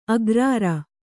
♪ agrāra